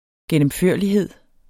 Udtale [ gεnəmˈføɐ̯ˀliˌheðˀ ]